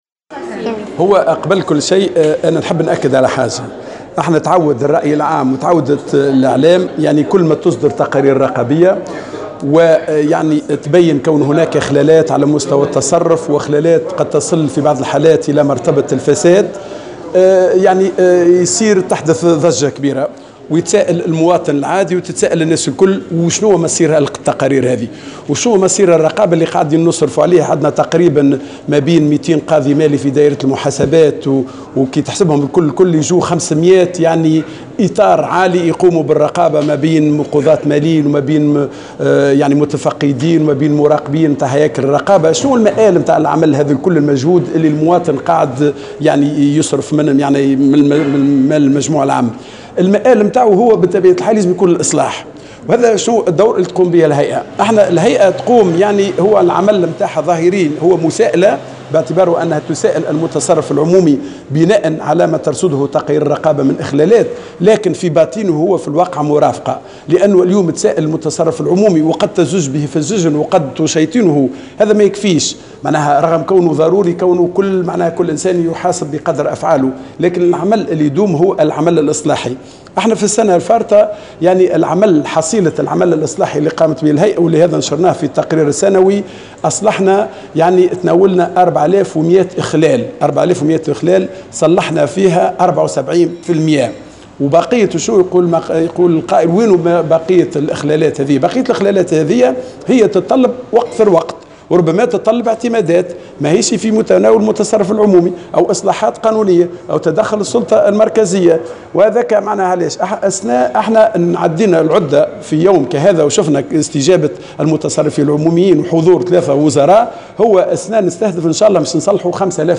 أكد رئيس الهيئة العليا للرقابة الادارية والمالية، كمال العيادي، في تصريح لمراسلة "الجوهرة أف أم" اليوم الأربعاء أن الهيئة رصدت 5 آلاف اخلال بالتصرف العمومي سيتم التدخل لإصلاحه. وأوضح أن هذه الإخلالات تتعلّق بنقائص بالتصرف العمومي بالمؤسسات العمومية على غرار سوء التصرف بالموارد البشرية والصفقات العمومية، مشيرا إلى تداعيات مالية لهذه الإخلالات. وتوقع ان تتمكّن الهيئة من اصلاح 85 % من هذه الإخلالات في ظرف 6 أشهر.